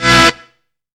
PUNCHY STAB.wav